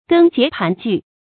根结盘据 gēn jié pán jù
根结盘据发音